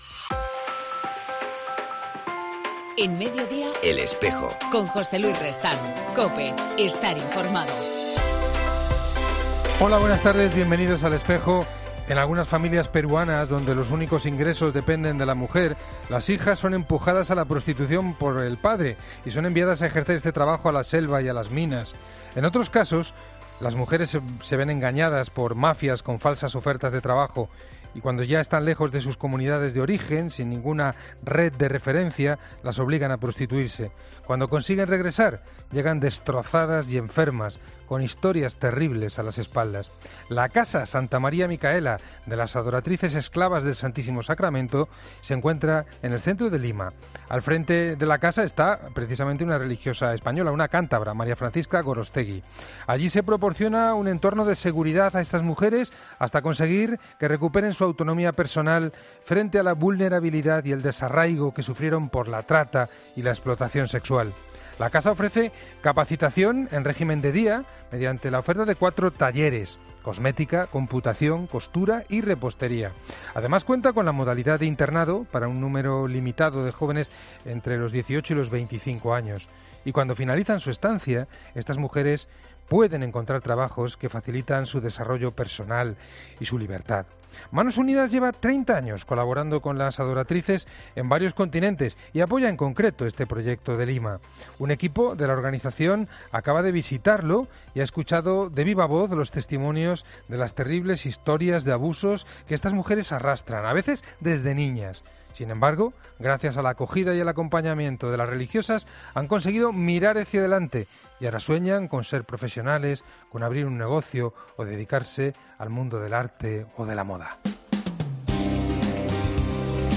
En El Espejo del 17 de mayo entrevistamos